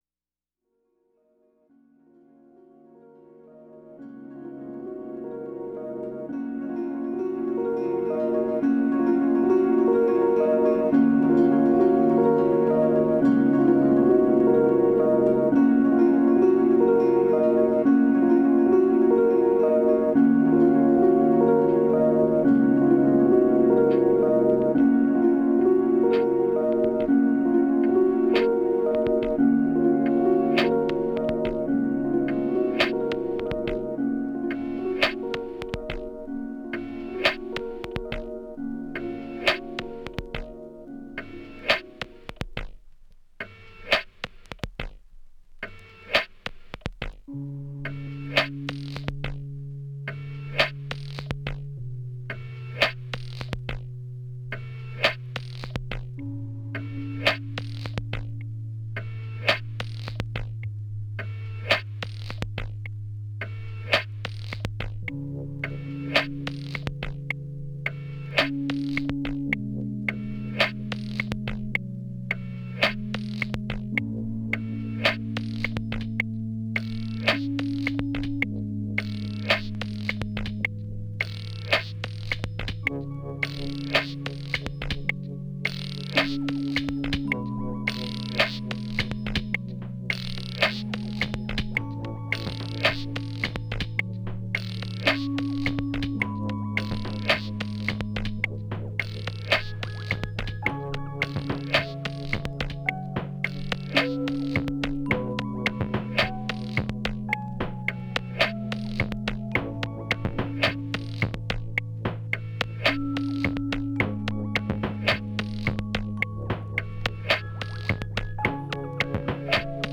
Downtempo Electronic Experimental Indie